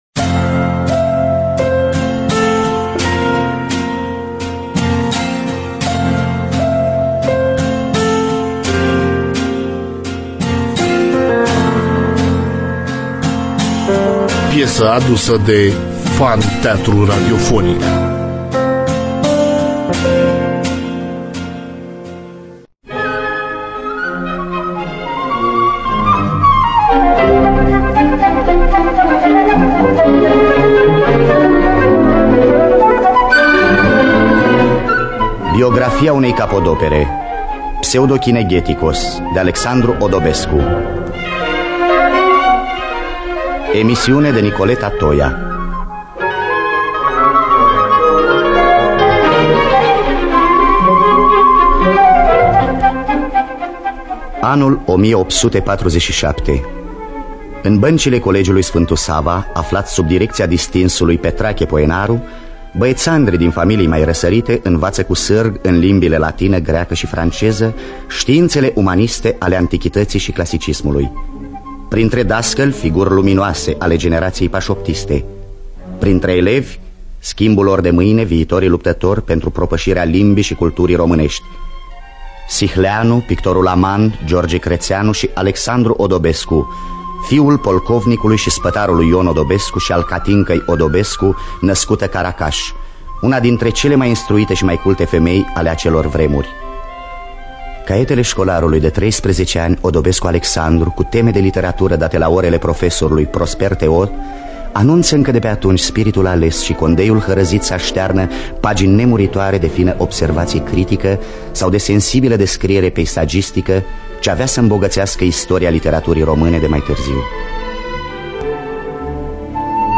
Biografii, Memorii: Alexandru Odobescu – Pseudokinegeticos (1983) – Teatru Radiofonic Online